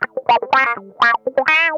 ITCH LICK 4.wav